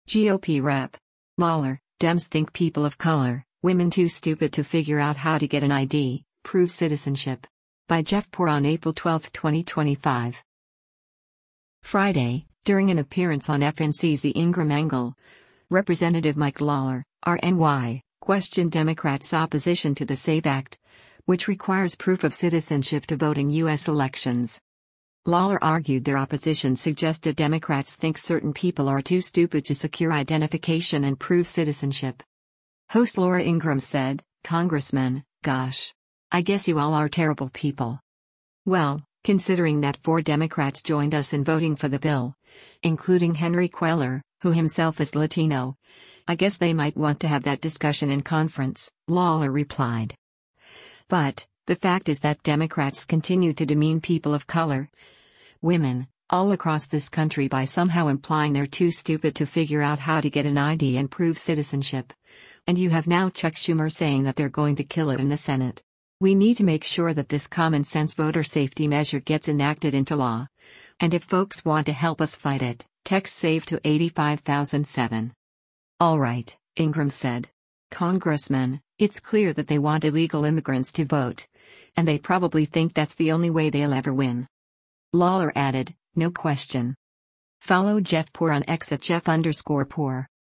Friday, during an appearance on FNC’s “The Ingraham Angle,” Rep. Mike Lawler (R-NY) questioned Democrats opposition to the SAVE Act, which requires proof of citizenship to vote in U.S. elections.